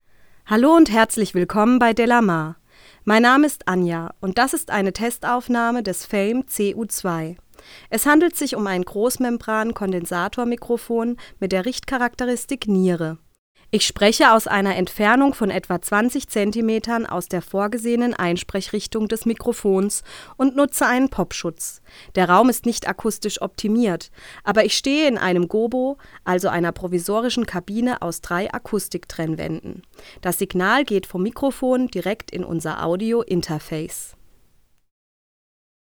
Sprache (weiblich)
Unsere Klangbeispiele entstanden aus einer Entfernung von etwa 20 Zentimetern und selbstverständlich in der vorgesehenen Einsprechrichtung für die Nierencharakteristik der Kapsel.
Der Sound ist hinreichend direkt und präsent, aber nicht harsch.
Das Eigenrauschen des Fame Studio CU2 ist bei normalkräftiger Abhörlautstärke in den Spiel- und Gesangspausen der Aufnahme hörbar.
fame_studio_cu2_test__sprache_f.mp3